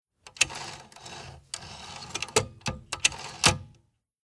Trolley release lever
trolley-release-lever.mp3